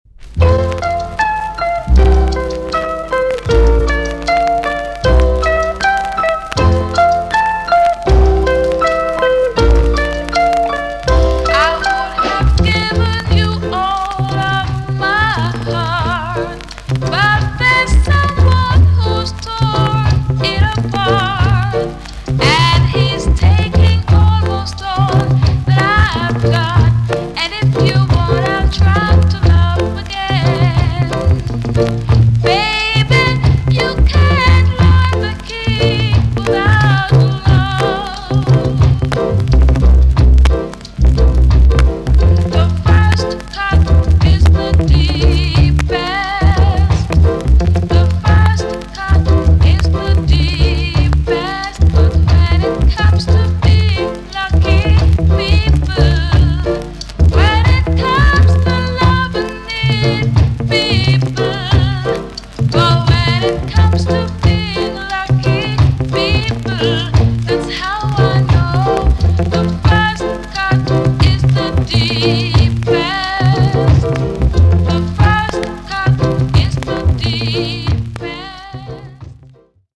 VG+~VG ok 軽いチリノイズが入ります。